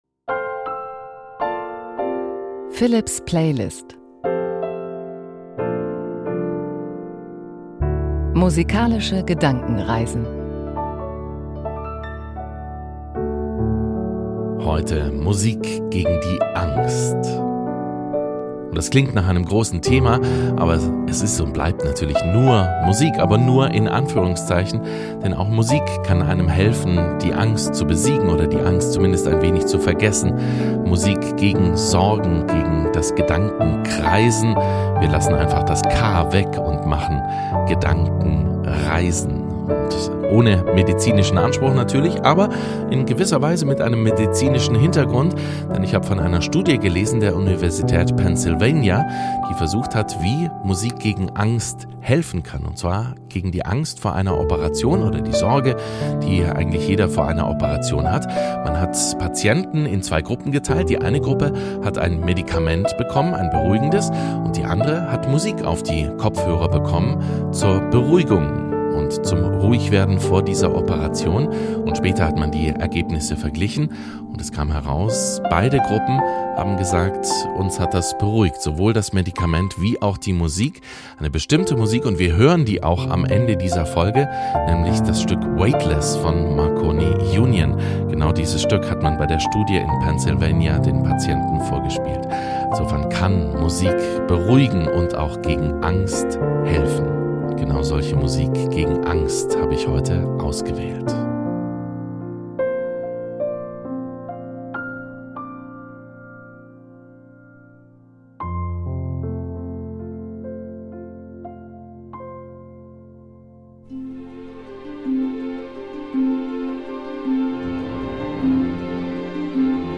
Klavier zum Einschlafen - 18.11.2022